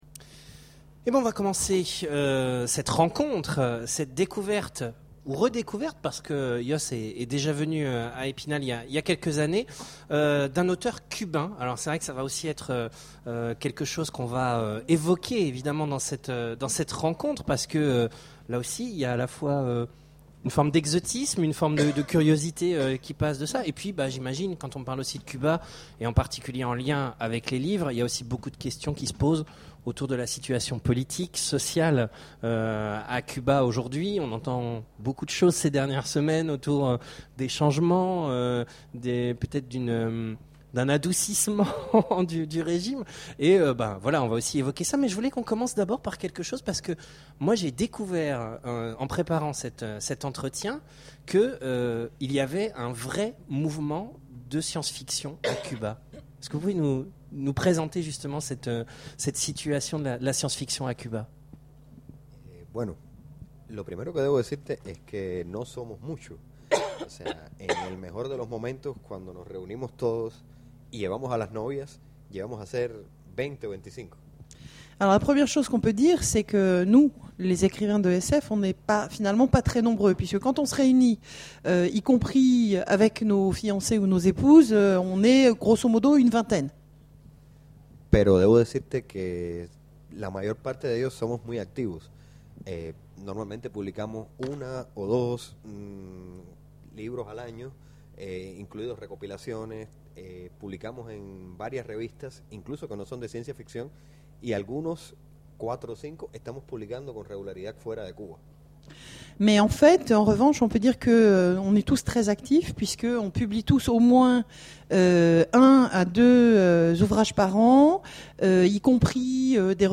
Imaginales 2011 : Conférence rencontre avec Yoss
Voici l'enregistrement de la rencontre avec Yoss